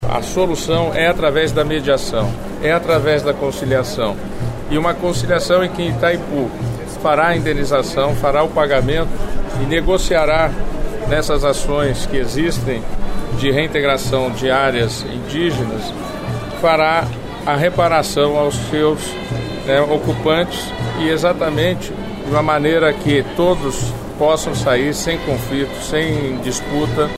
A cerimônia que oficializou o acordo foi realizada em uma escola indígena localizada em uma aldeia na cidade de Itaipulândia.
O ministro do STF, Dias Toffoli, afirmou que a homologação do acordo marca uma mudança na forma como os conflitos fundiários são tratados no país.